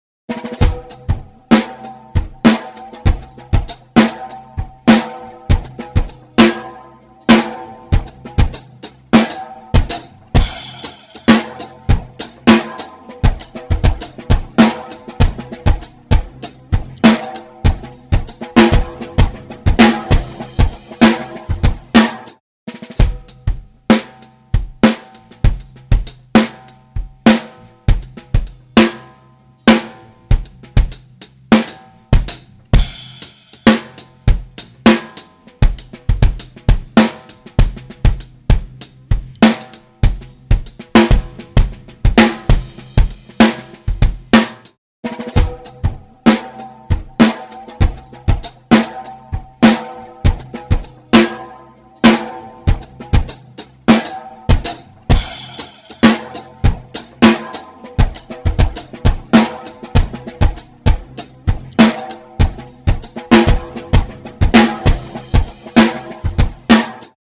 豊潤なリバーブと、引き裂くようなディストーション
MangledVerb | Drums | Preset: Revunched
MangledVerb-Drums-Preset-Revunched.mp3